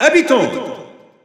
Announcer pronouncing female Villager in French.
Villager_F_French_Announcer_SSBU.wav